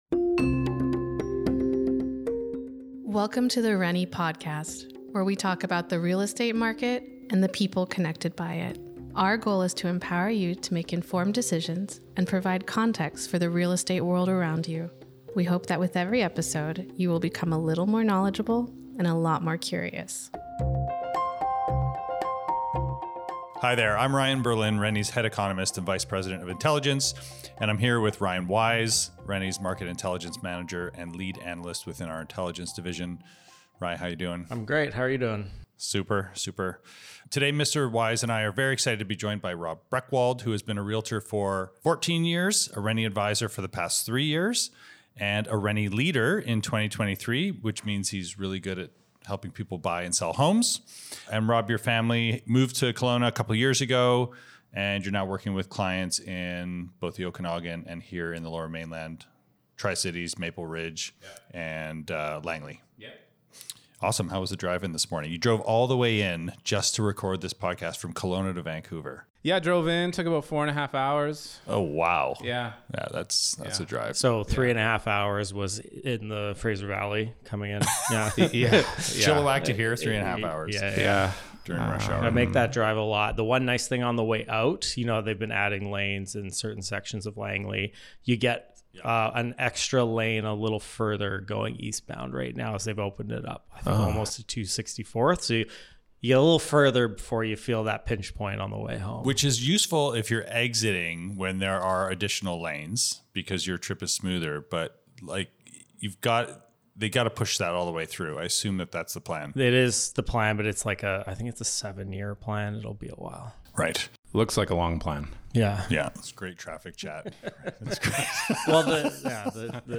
Listen now kelowna's housing supply, uncorked 2024-07-30 • Episode 65 0:00 0 Subscribe  All episodes The rennie podcast is about the real estate market and the people connected by it. Tune in for monthly discussions making sense of the latest market data.